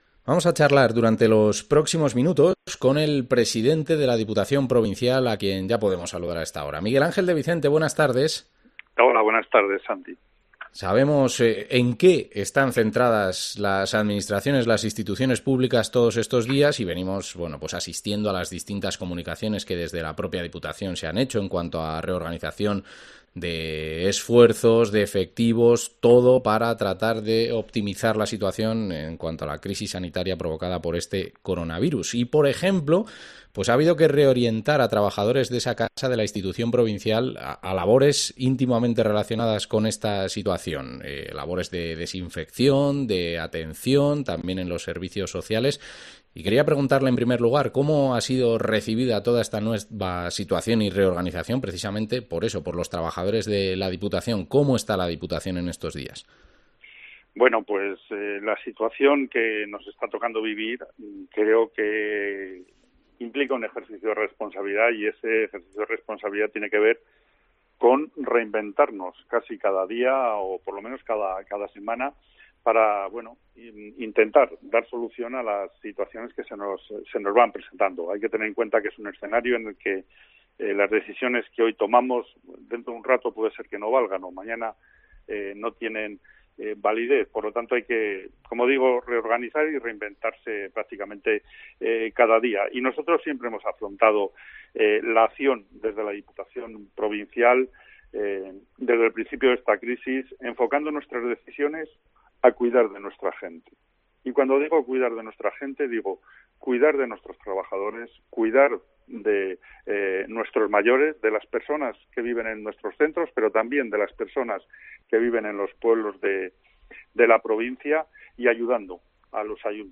Entrevista al presidente de la Diputación, Miguel Ángel de Vicente